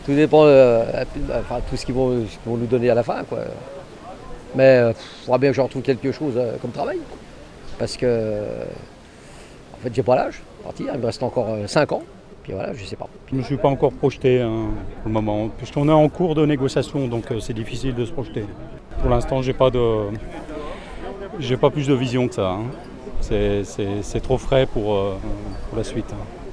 Ce lundi 16 juin, les salariés de l’entreprise du groupe Rossignol ont manifesté dans les rues de Sallanches.